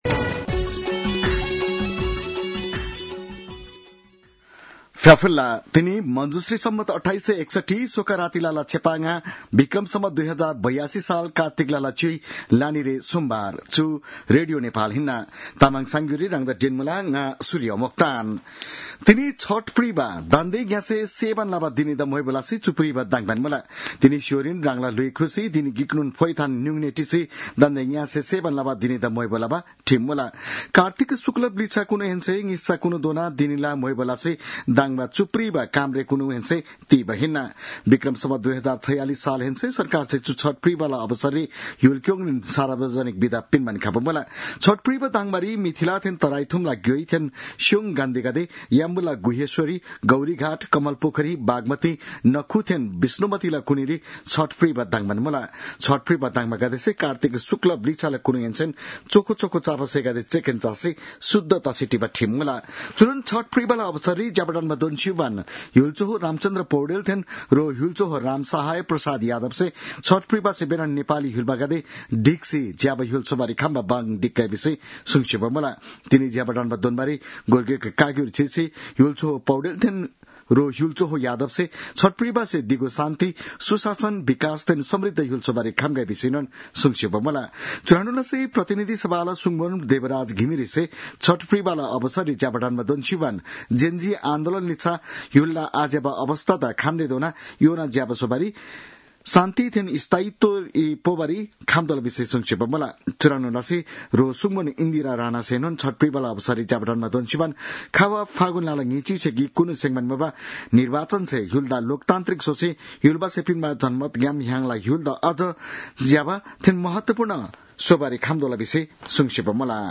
तामाङ भाषाको समाचार : १० कार्तिक , २०८२